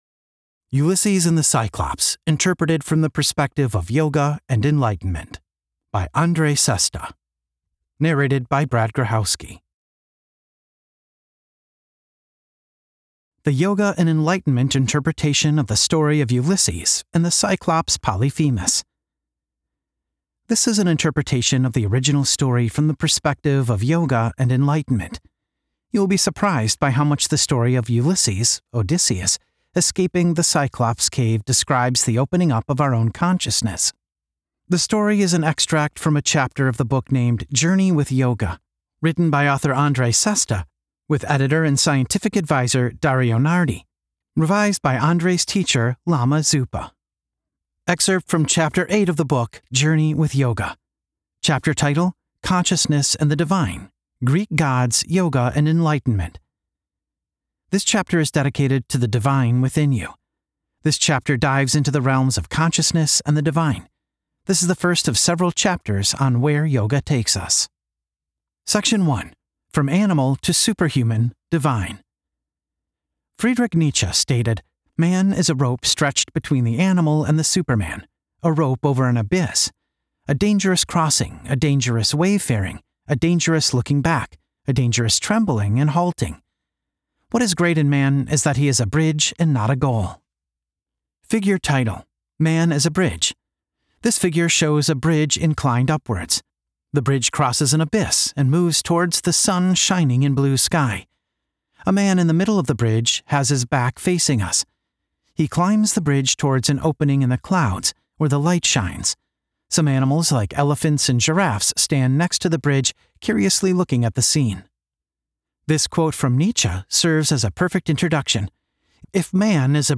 The story narrated in the mp3 audiobook and the mp4 video are slighly summarised when compared to the chapter excerpt in html format.
sample_chapter_audio_book.mp3